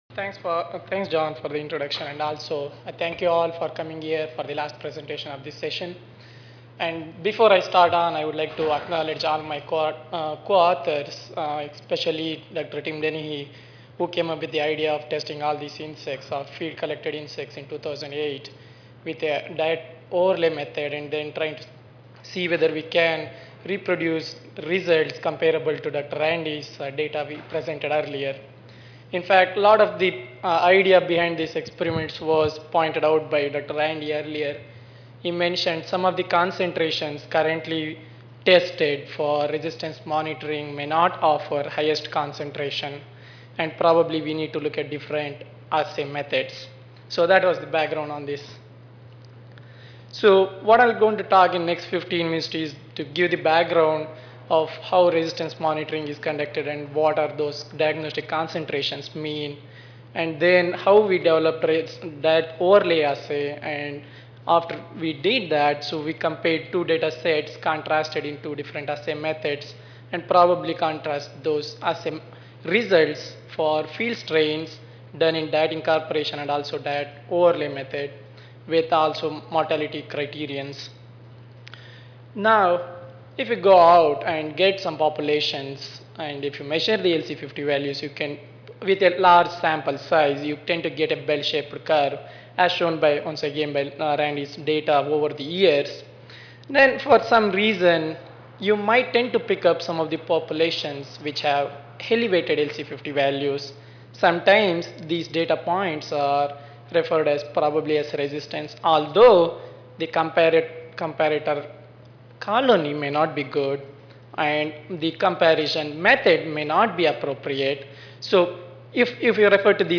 Salon J (Marriott Rivercenter Hotel)
Recorded presentation